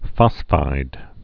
(fŏsfīd)